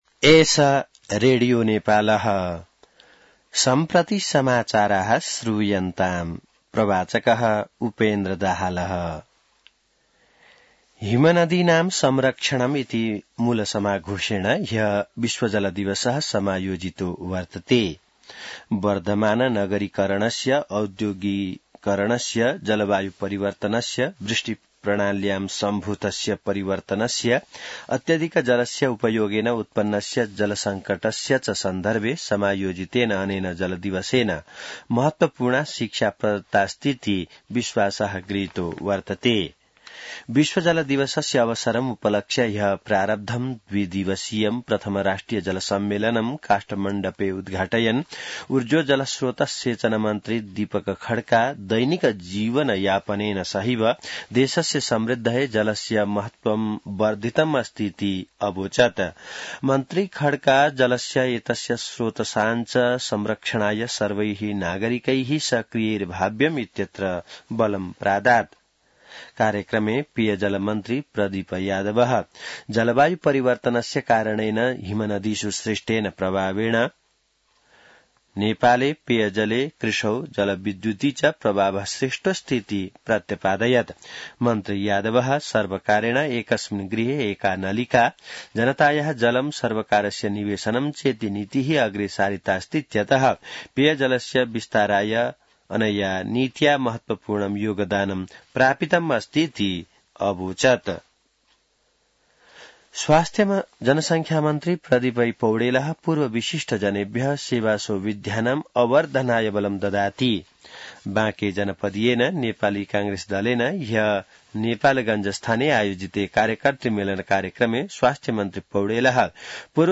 संस्कृत समाचार : १० चैत , २०८१